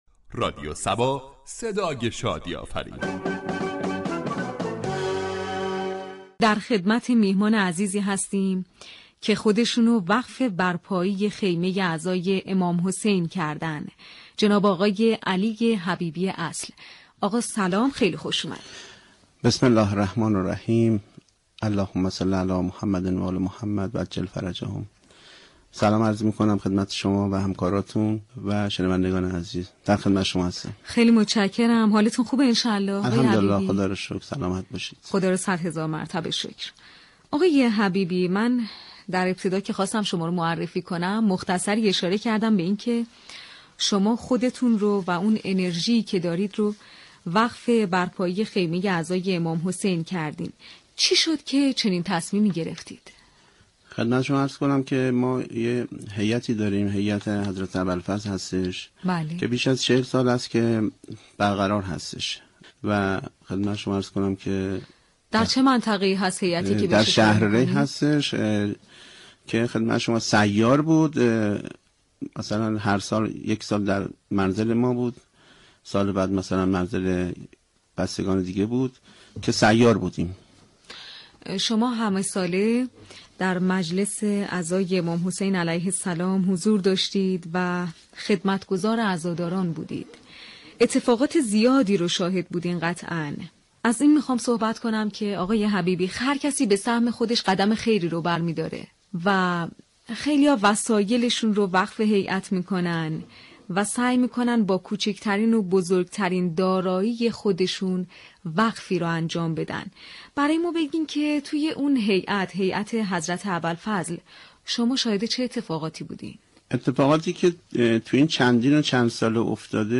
"میزبان" برنامه ای است كه با خیرین در حوزه های مختلف گفتگو می كند